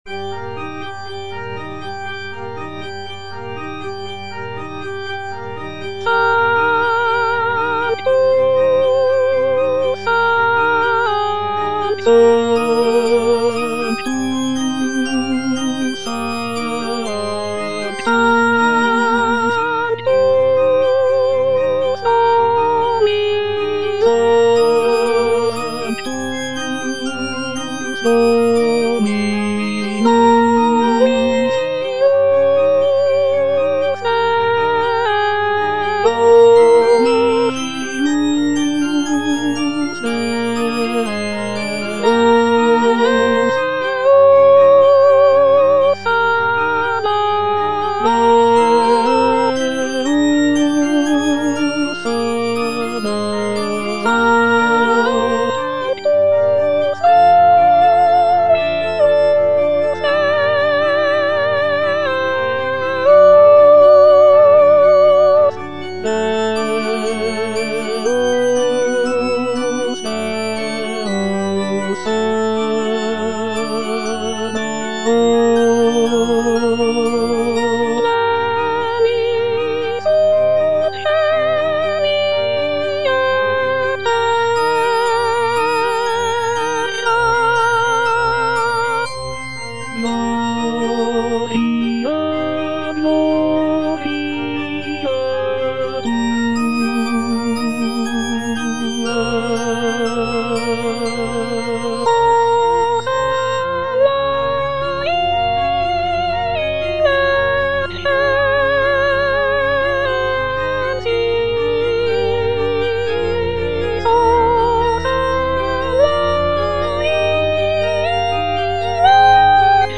G. FAURÉ - REQUIEM OP.48 (VERSION WITH A SMALLER ORCHESTRA) Sanctus - Soprano (Emphasised voice and other voices) Ads stop:
Gabriel Fauré's Requiem op. 48 is a choral-orchestral work that is known for its serene and intimate nature.
This version features a reduced orchestra with only a few instrumental sections, giving the work a more chamber-like quality.